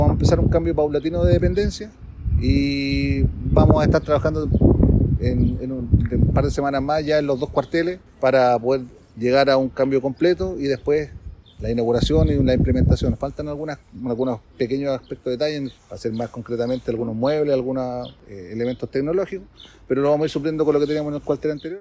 El jefe de zona de Carabineros, general Carlos Contreras, señaló que la ocupación del recinto será de manera paulatina a través de una marcha blanca, dado que aún falta la implementación de algunos equipamientos.